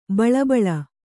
♪ baḷabaḷa